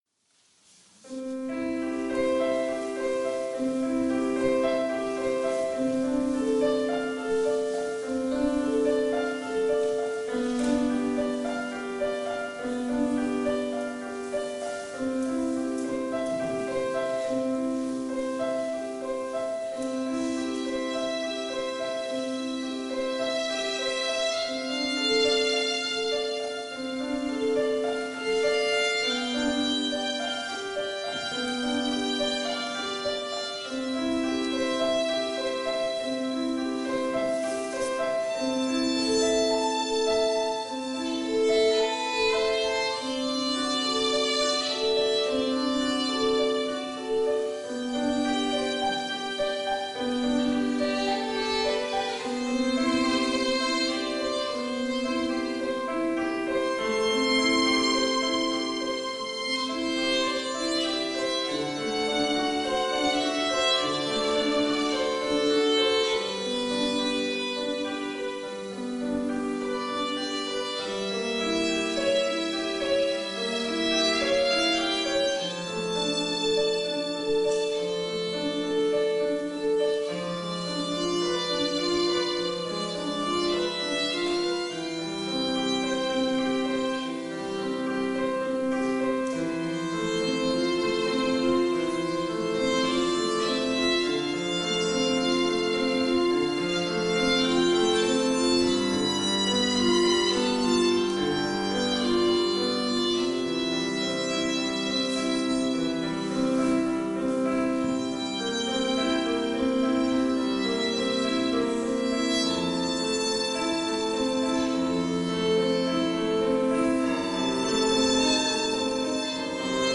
Adventsingen Sa. 16.Dez. 2023 Spitalskirche